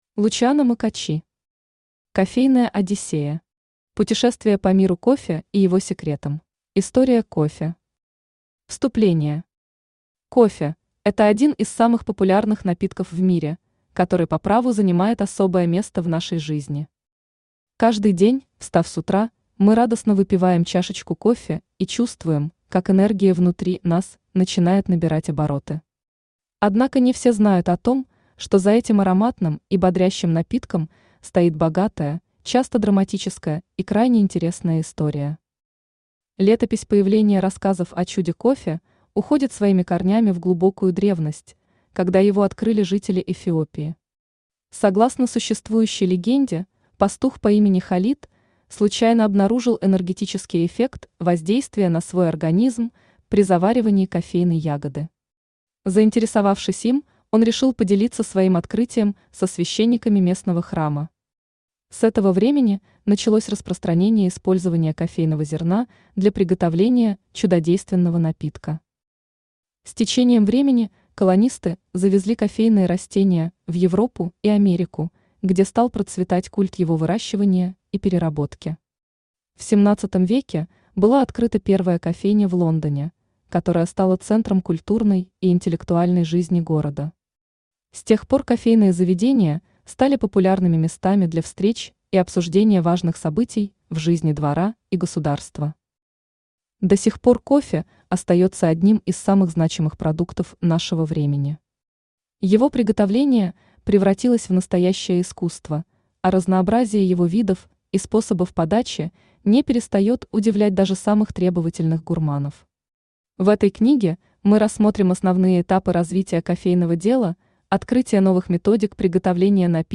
Аудиокнига Кофейная Одиссея. Путешествие по миру кофе и его секретам | Библиотека аудиокниг
Путешествие по миру кофе и его секретам Автор Лучиано Маккочи Читает аудиокнигу Авточтец ЛитРес.